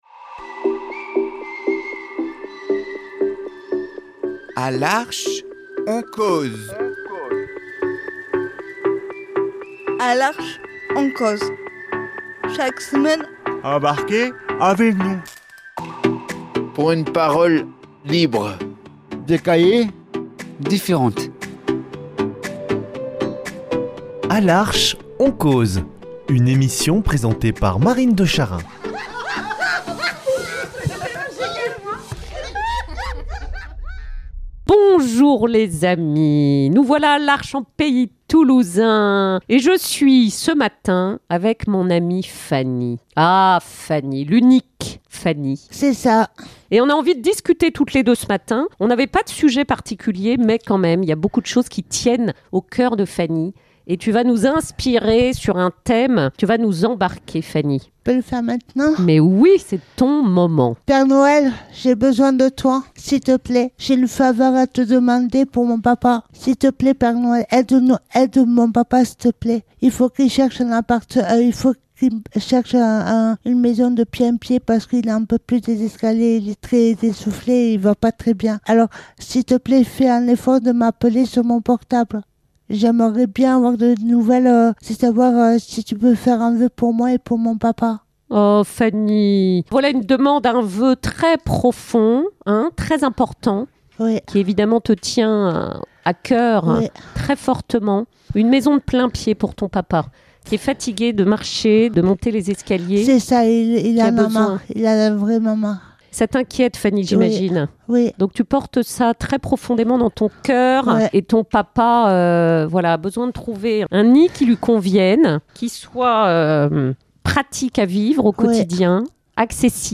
Une émission présentée par Arche en pays Toulousain